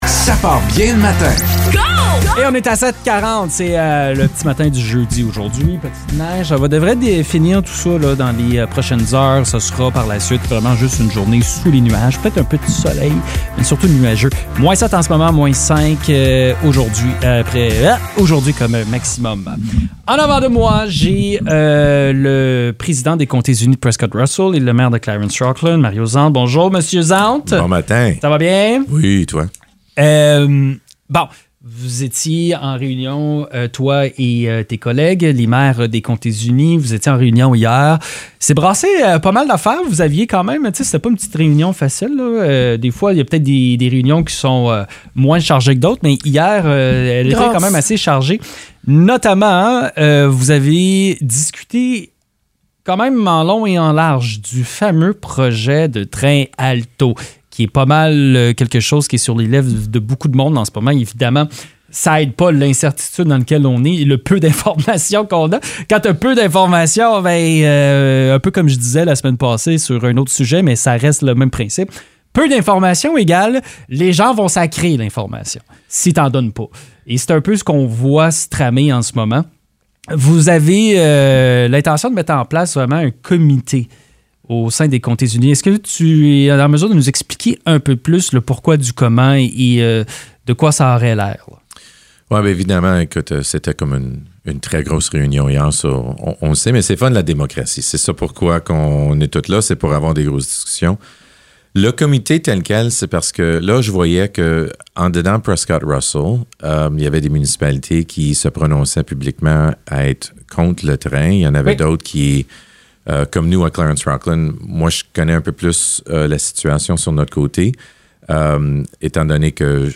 Il a notamment été question du projet de train à grande vitesse Alto. Un comité sera mis en place afin de représenter les intérêts de Prescott-Russell auprès de la société d’État, dans le but d’assurer que les réalités et priorités régionales soient prises en compte dans le développement du futur TGV. L’entrevue a également permis d’aborder la mutualisation potentielle des services d’incendie dans la région, le projet de dôme sportif à Rockland, ainsi que le projet d’élargissement de la route 17.